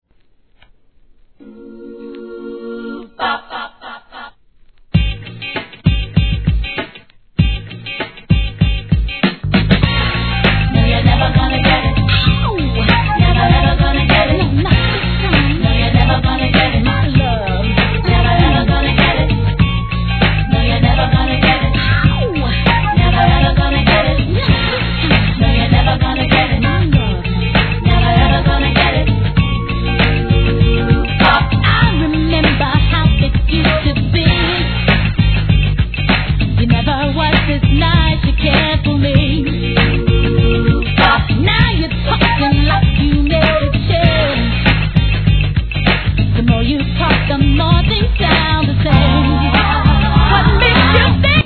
HIP HOP/R&B
FUNKYなビートに気持ちいいフルートとヴォーカルが見事◎